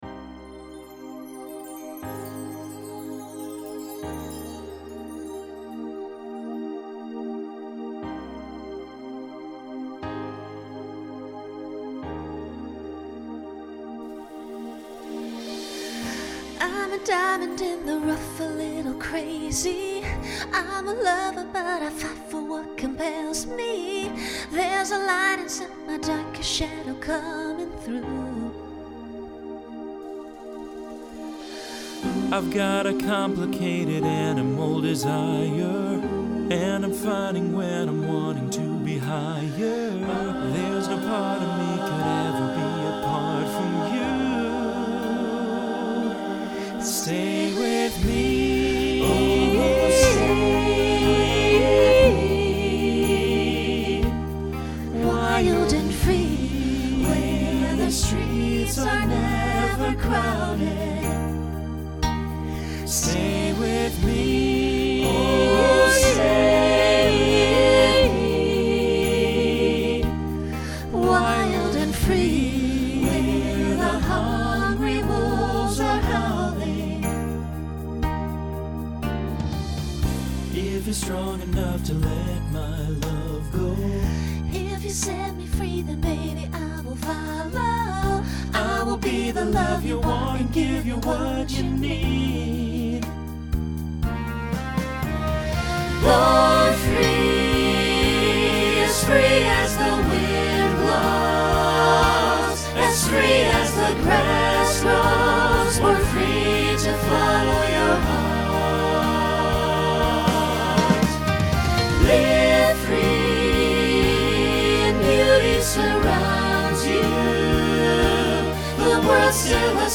Genre Pop/Dance
Function Ballad Voicing SATB